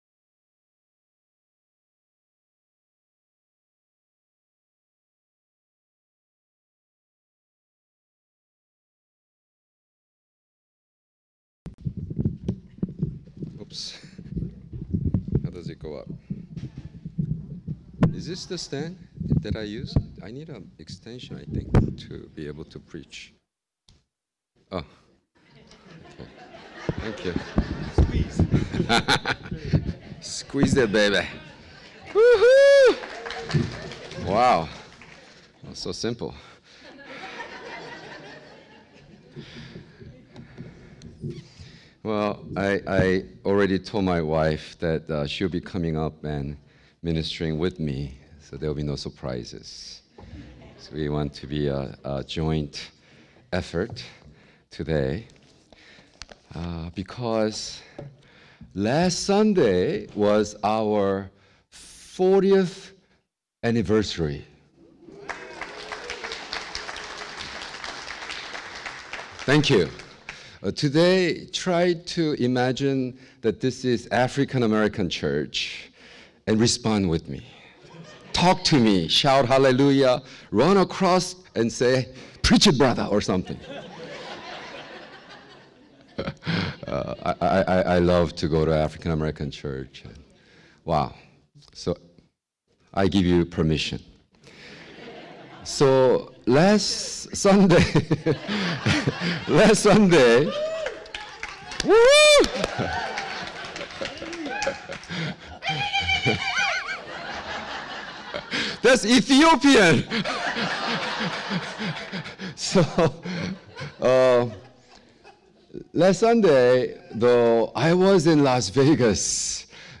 Sermons | Catalyst Christian Community